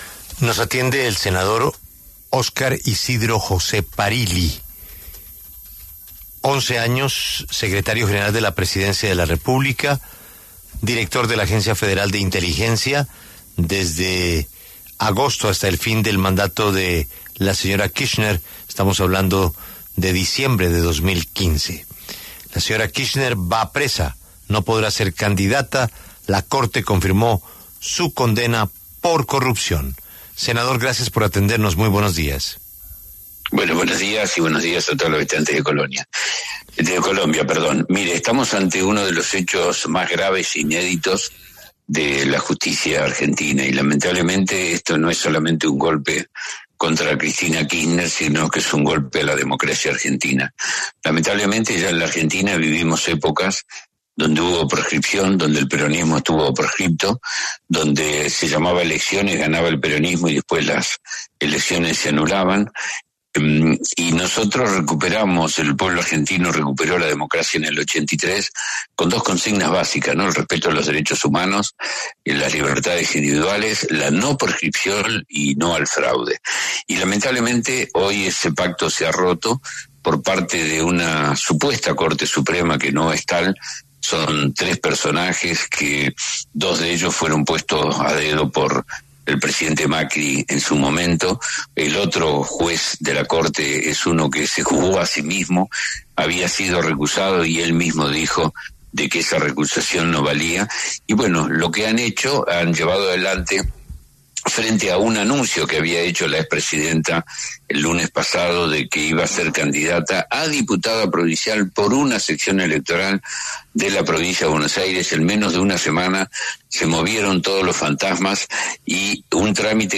El senador argentino Oscar Isidro José Parrilli afirmó ante La W que la decisión de la Corte Suprema de Argentina estuvo llena de irregularidades.